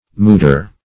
Search Result for " mudar" : The Collaborative International Dictionary of English v.0.48: Mudar \Mu"dar\, n. [Hind. mad[=a]r.]